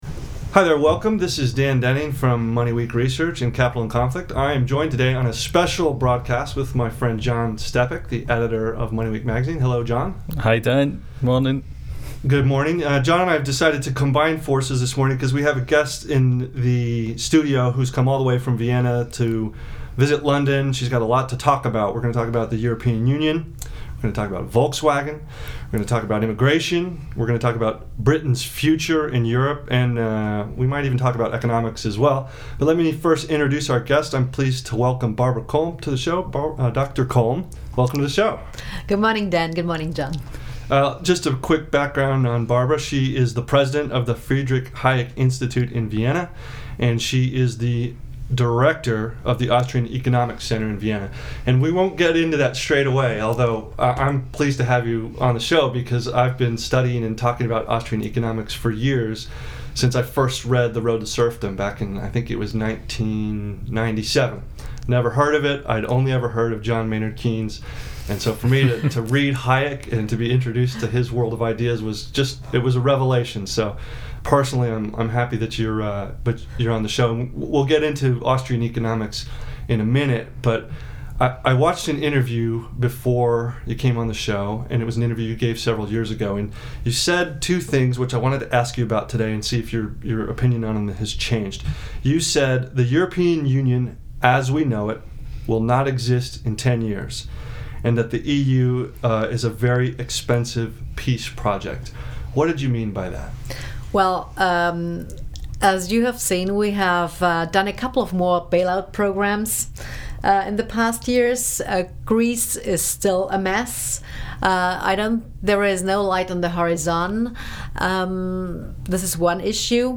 She dropped by the MoneyWeek studio for a 40-minute chat.